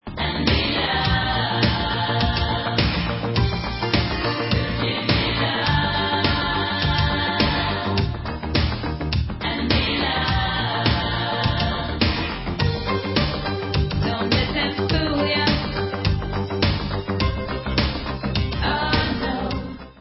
sledovat novinky v oddělení Pop